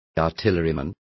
Complete with pronunciation of the translation of artillerymen.